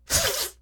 launch.ogg